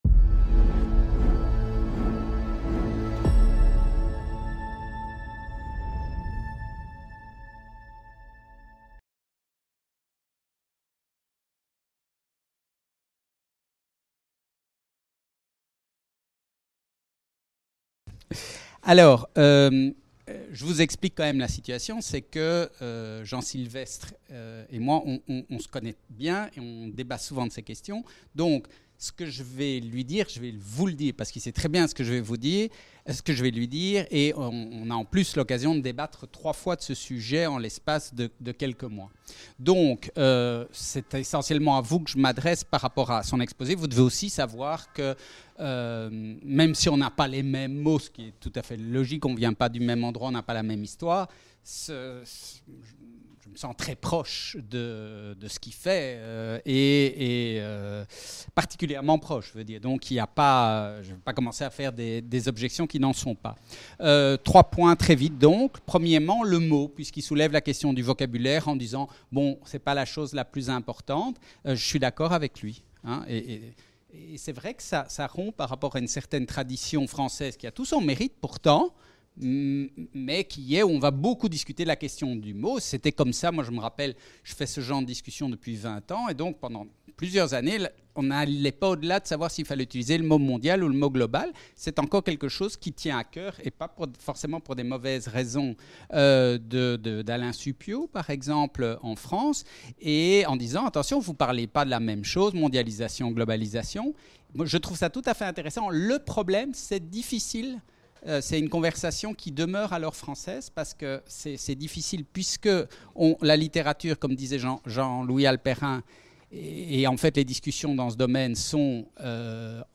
Cours/Séminaire